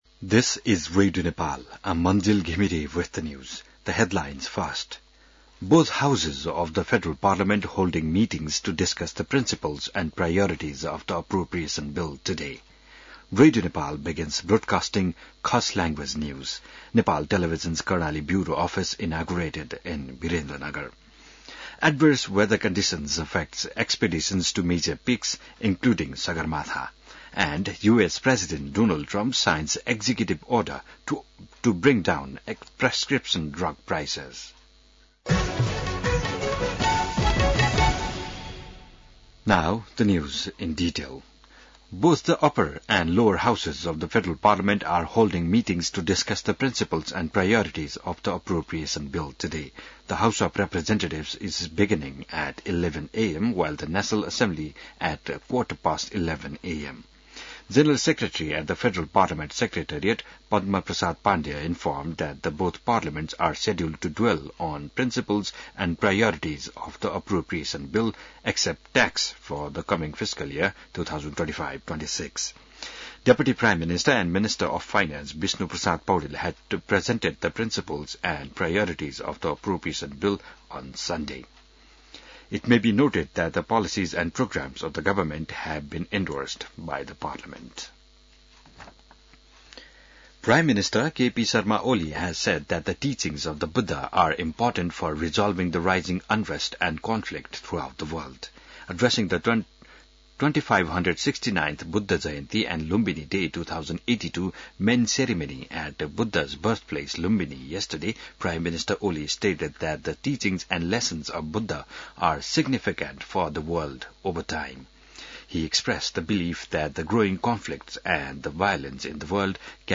बिहान ८ बजेको अङ्ग्रेजी समाचार : ३० वैशाख , २०८२